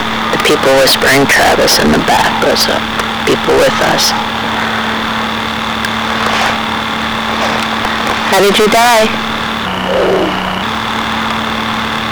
These recordings were taken using a General Electric Microcassette Recorder, Model #3-5326A using a Radio Shack MC-60 Microcassette at normal speed.
"How did you die?" Answered slowed using Audacity.